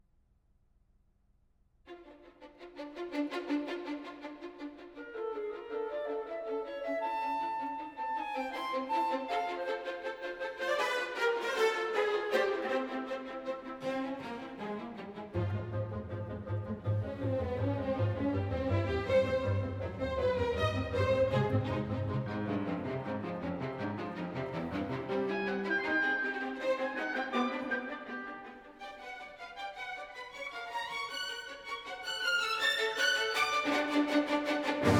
Жанр: Классика
Orchestral